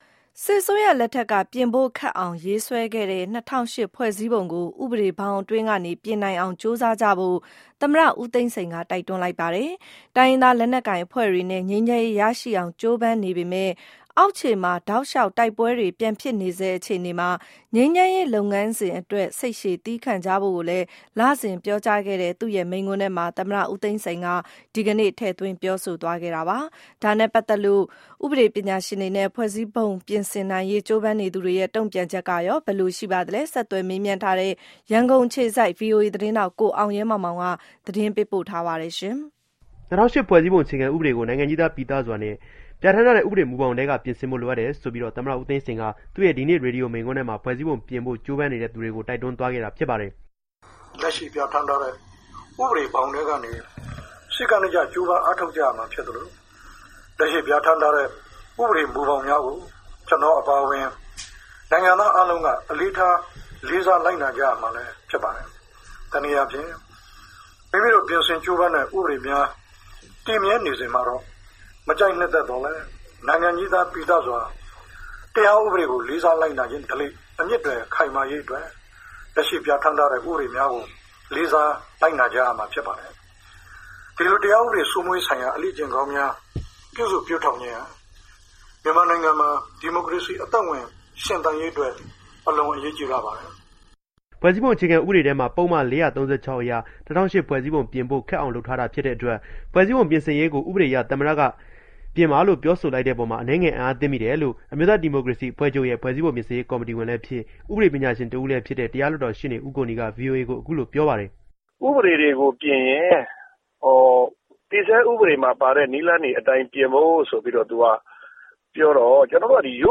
U Thein Sein Speech